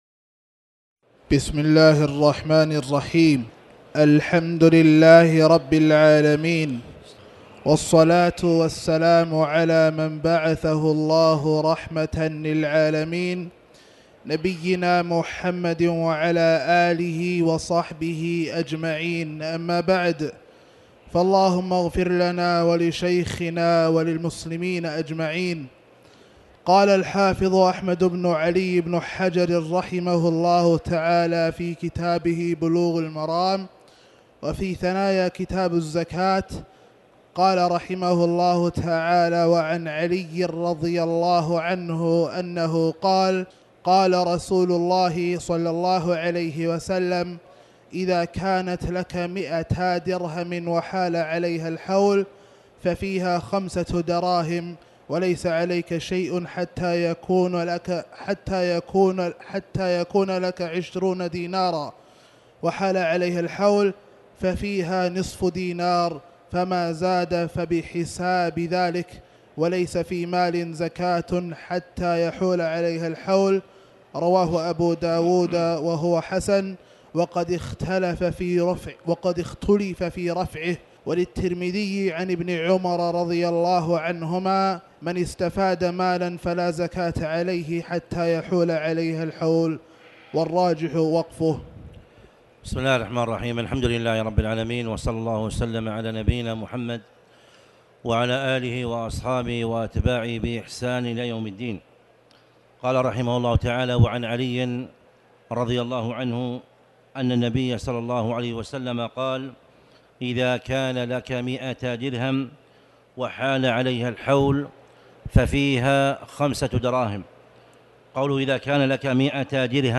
تاريخ النشر ٢٨ جمادى الآخرة ١٤٣٩ هـ المكان: المسجد الحرام الشيخ